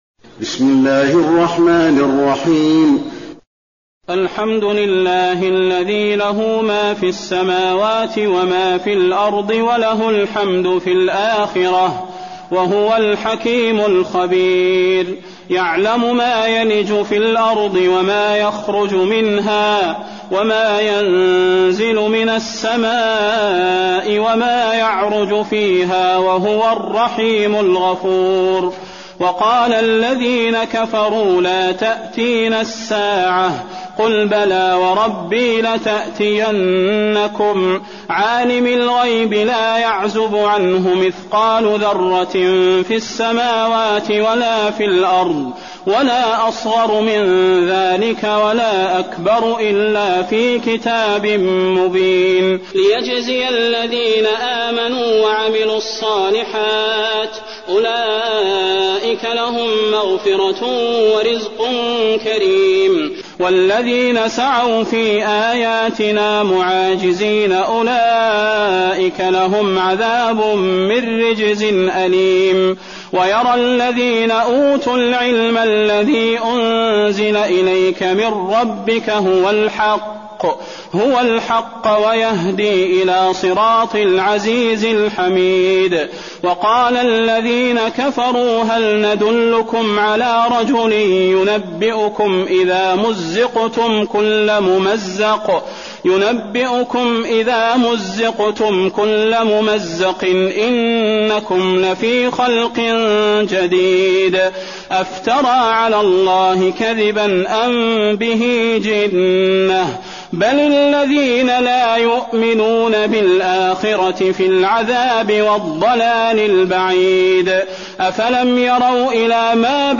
المكان: المسجد النبوي سبأ